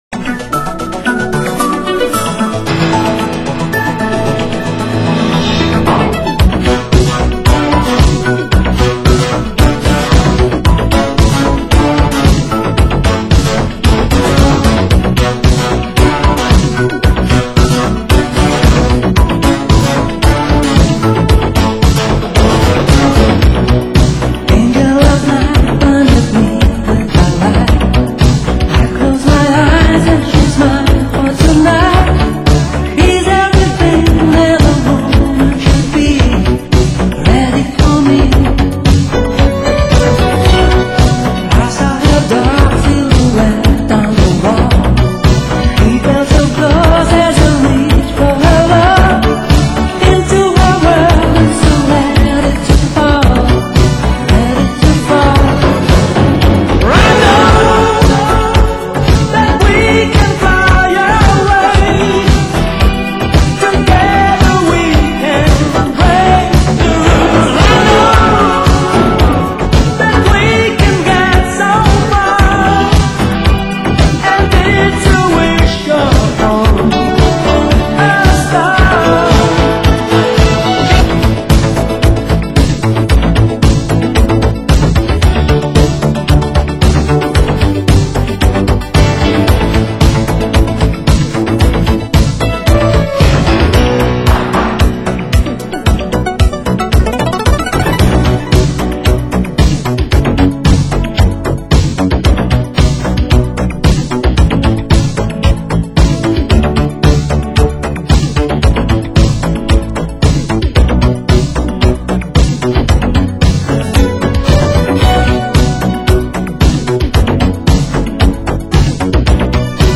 Genre: Italo Disco